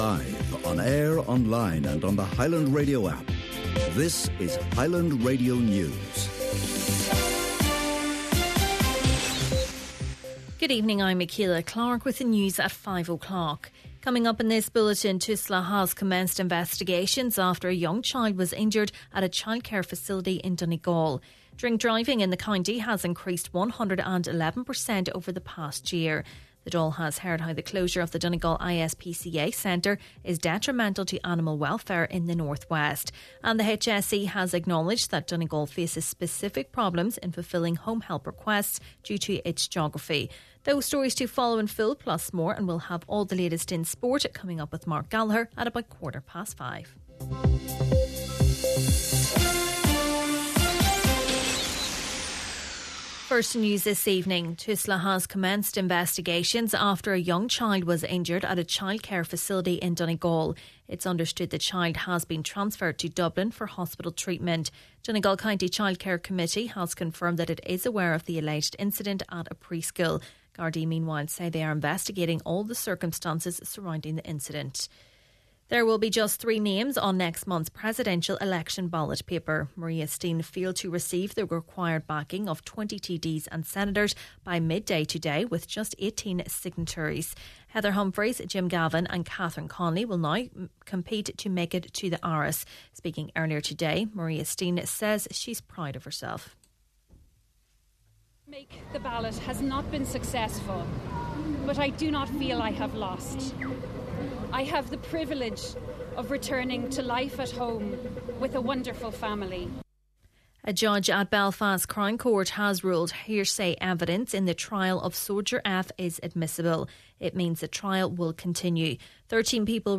Main Evening News, Sport and Obituaries – Wednesday, September 24th